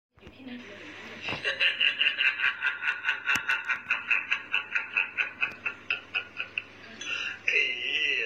Laughing